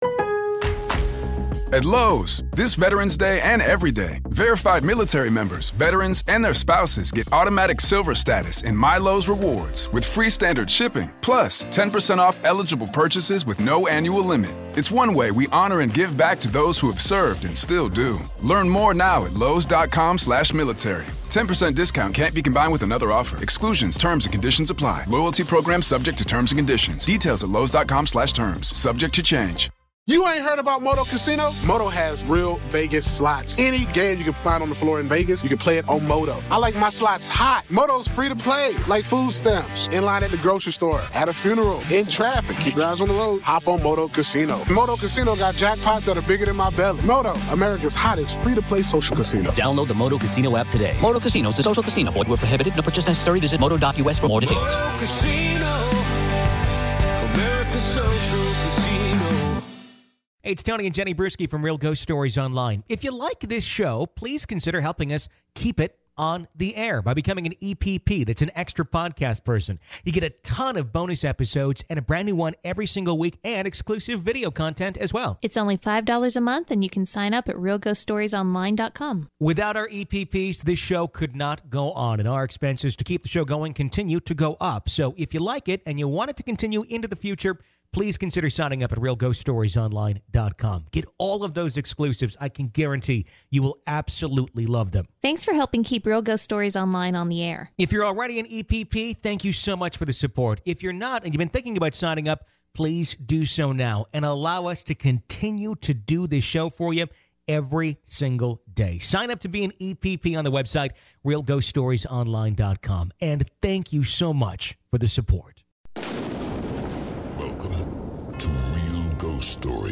We take your calls during open lines and share your real ghost stories!